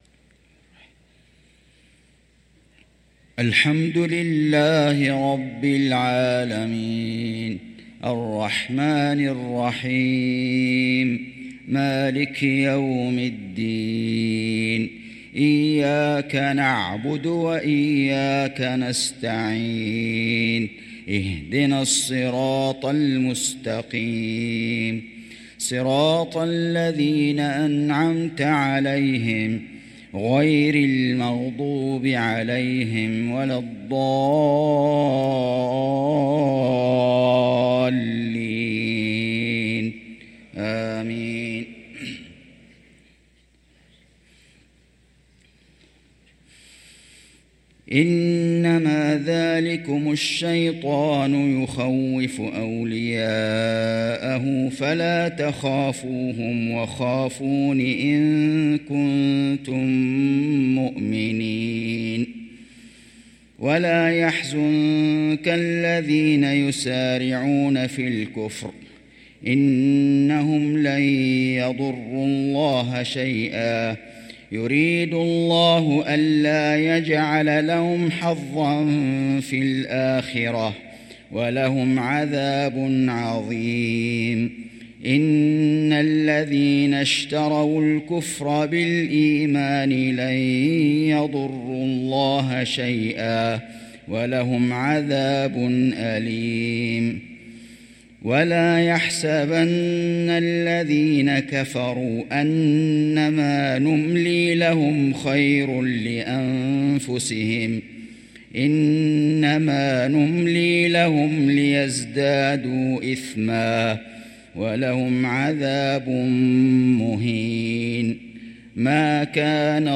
صلاة العشاء للقارئ فيصل غزاوي 7 جمادي الأول 1445 هـ
تِلَاوَات الْحَرَمَيْن .